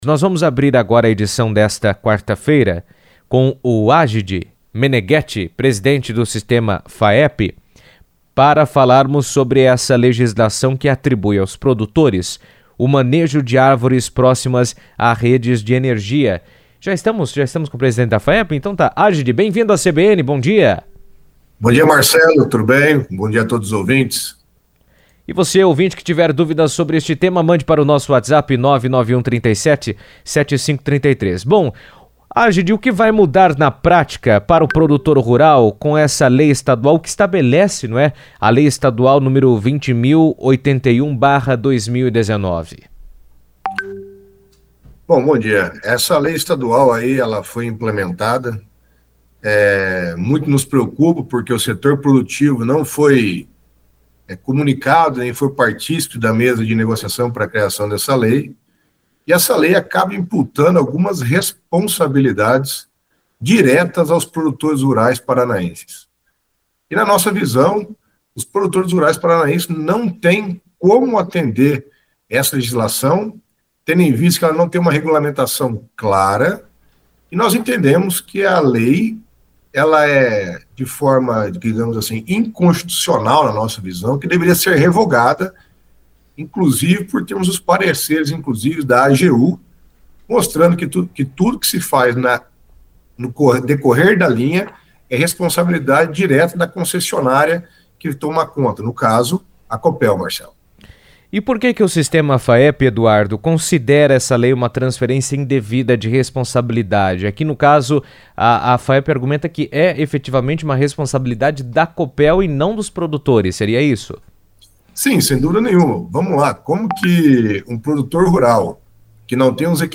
entrevista à CBN